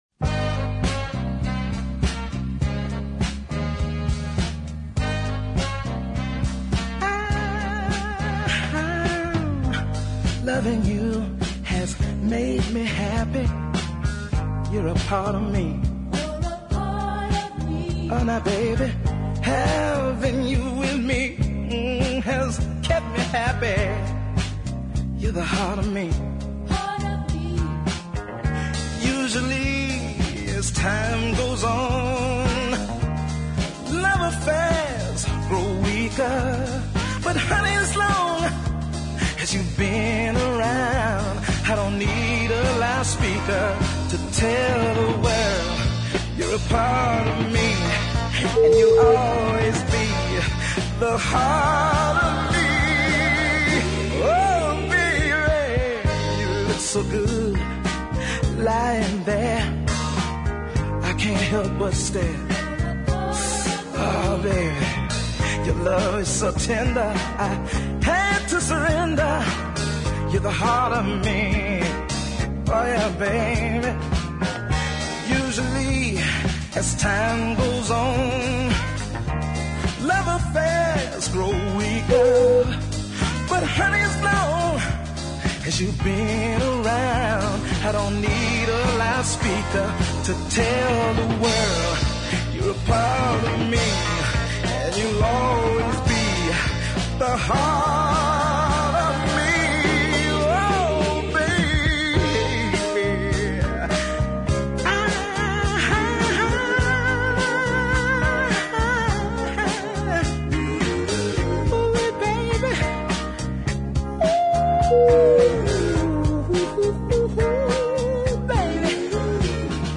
authentic southern soul
fine baritone voice